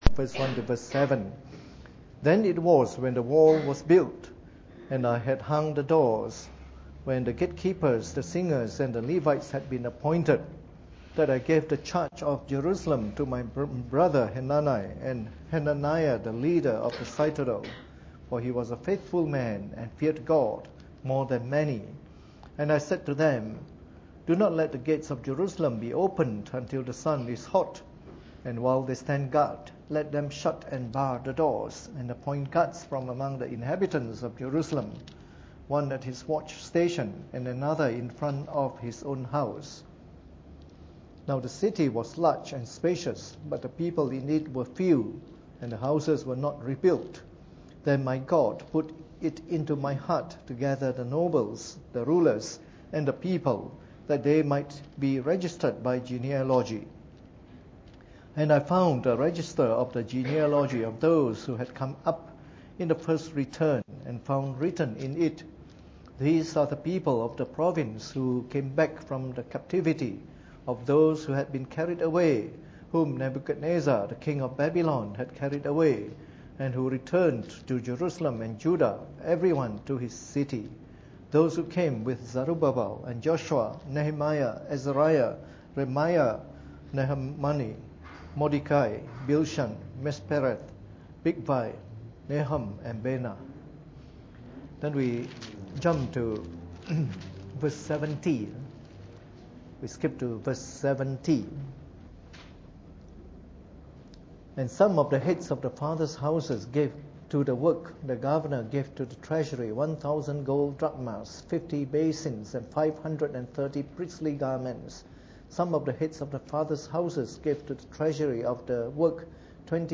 Preached on the 11th of June 2014 during the Bible Study, from our series of talks on the Book of Nehemiah.